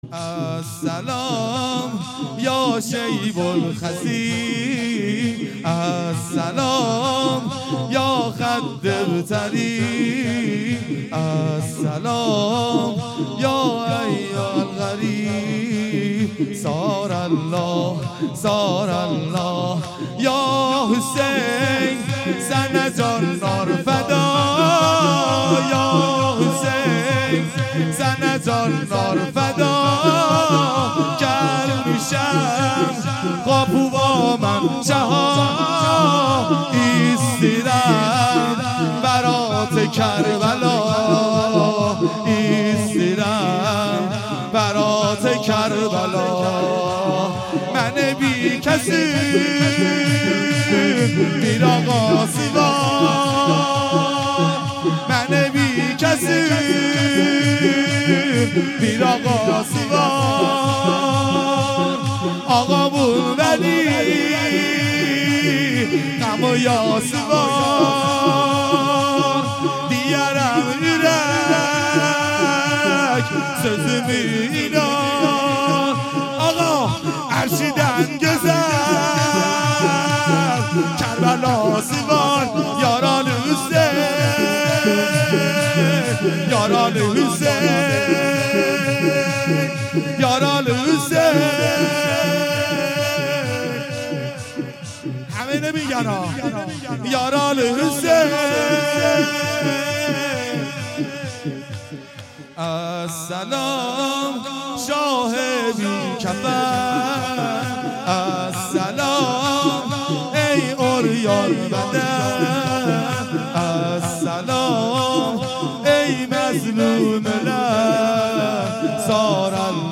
مراسم سیاهپوشان ودهه اول محرم۹۷هییت شباب الحسین